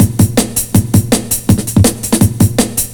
JUNGLE4-L.wav